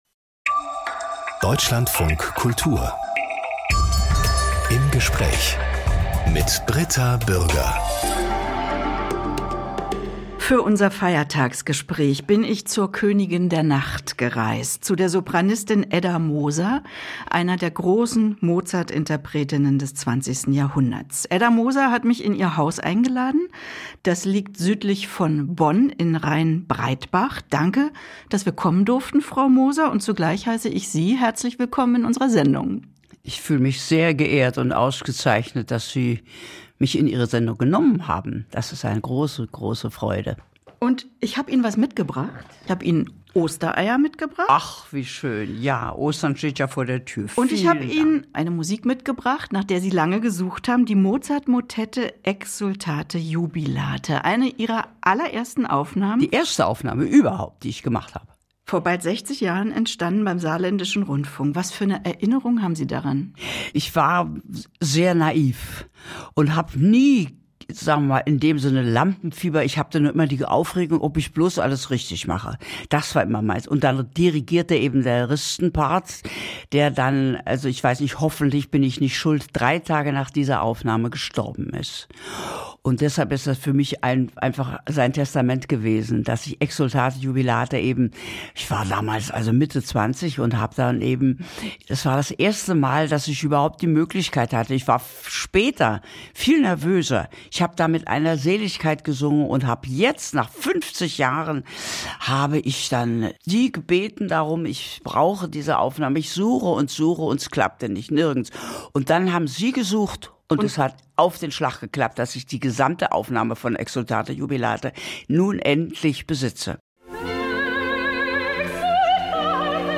Aus dem Podcast Im Gespräch Podcast abonnieren Podcast hören Podcast Im Gespräch Eine ganze Stunde widmen wir einer Person.